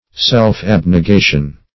self-abnegation - definition of self-abnegation - synonyms, pronunciation, spelling from Free Dictionary
Self-abnegation \Self`-ab`ne*ga"tion\, n.